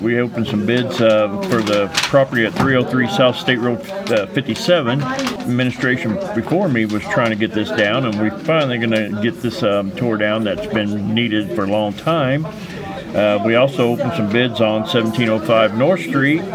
The City of Washington will see two run-down structures taken down after action by the Board of Public Works and Safety.  Mayor David Rhoads explains.